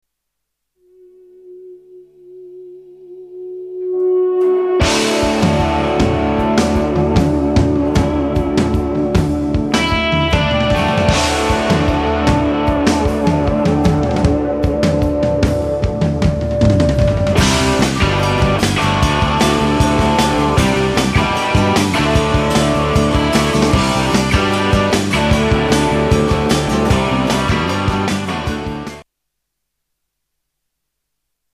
STYLE: Pop
a turbulent rhythmic rocker